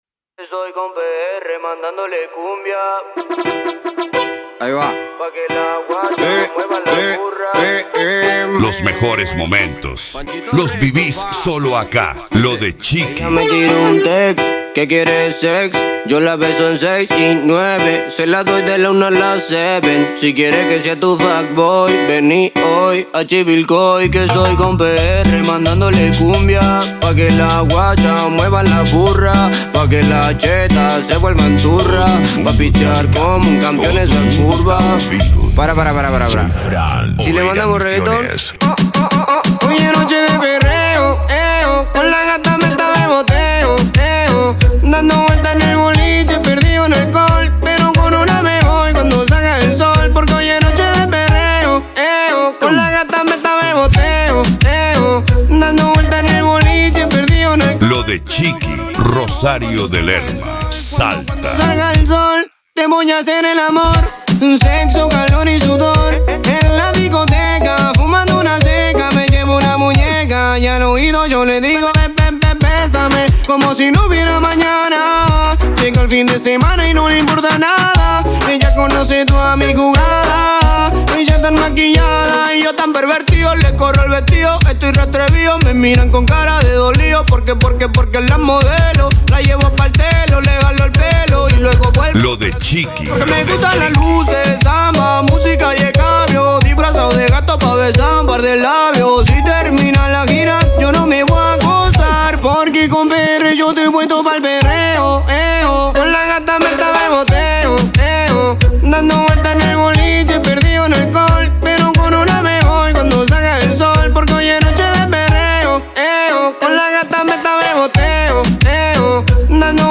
Remix
Retro Music